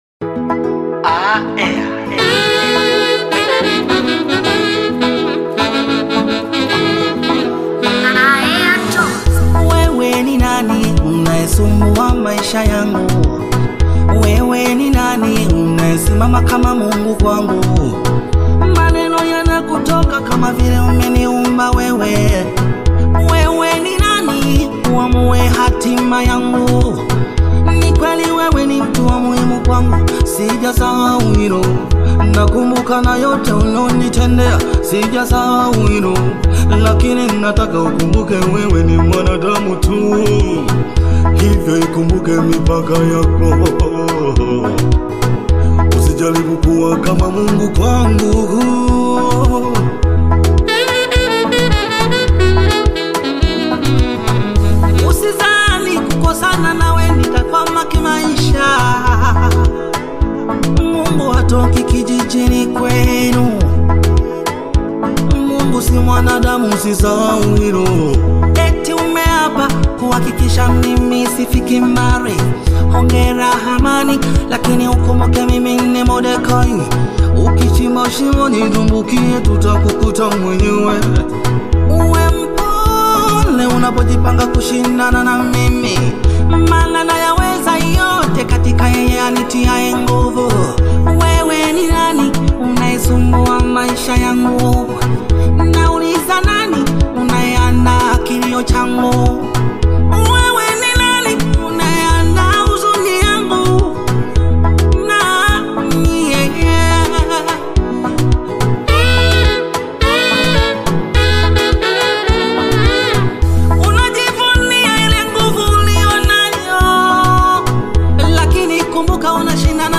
Tanzanian gospel singer and songwriter
gospel song